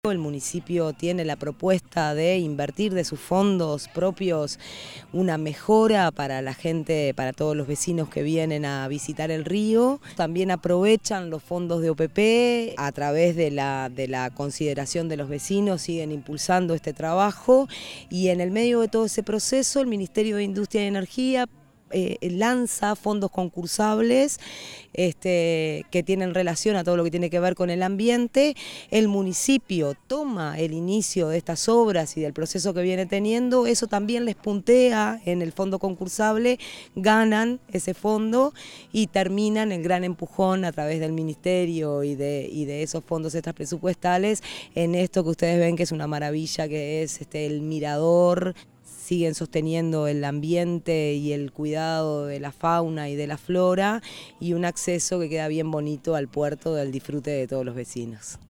Con la presencia de autoridades nacionales, departamentales, locales, vecinas y vecinos, se realizó la inauguración de El Puertito en el Municipio de Aguas Corrientes.